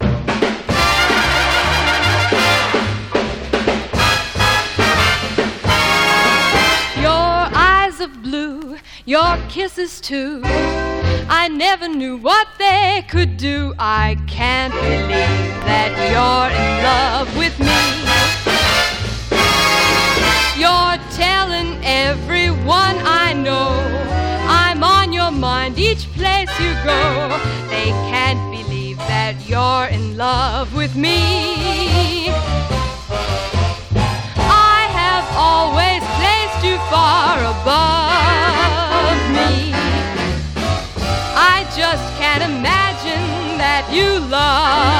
Jazz, Big Band, Cool Jazz　USA　12inchレコード　33rpm　Mono